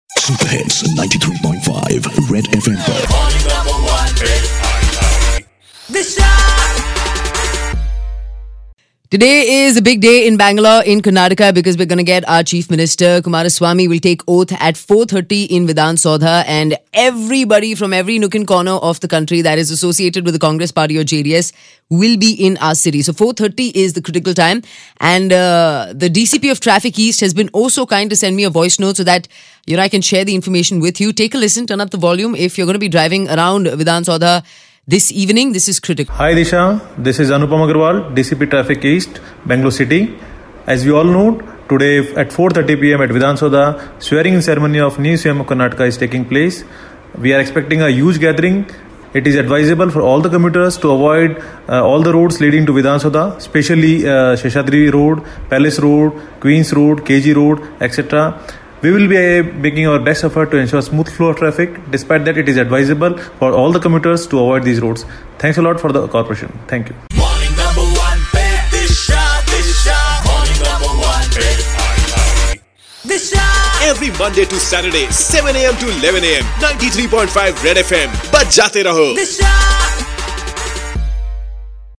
DCP Anupam Agarwal gives details of Oath Ceremony today at 4.30 pm and Traffic Update